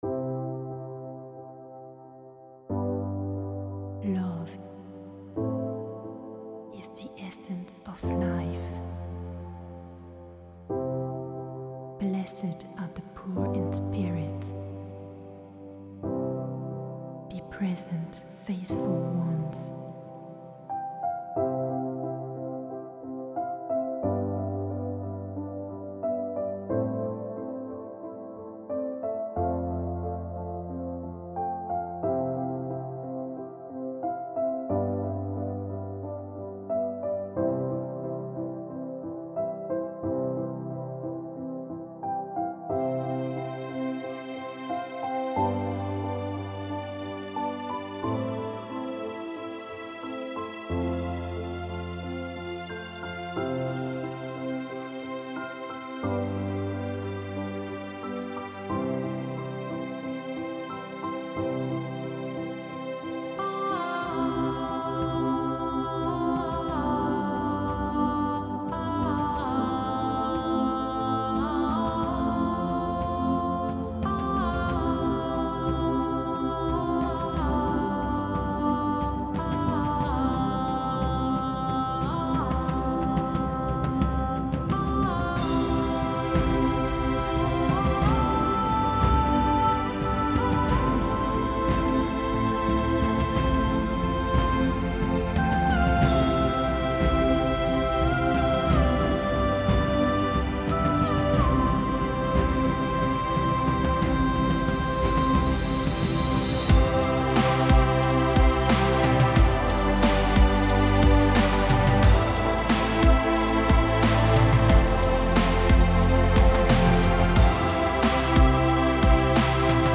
Genre: Ambient.